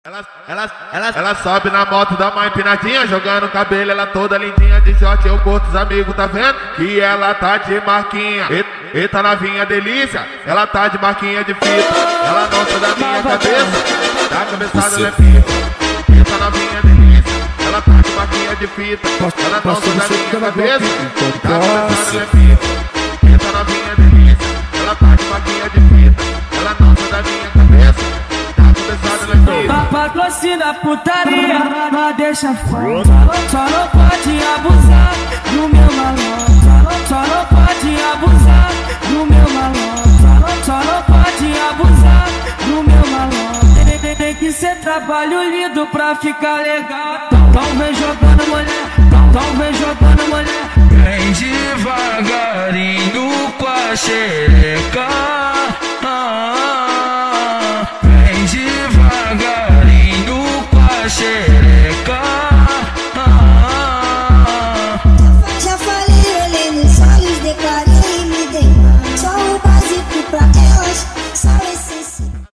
فانک شنیدنی
فانک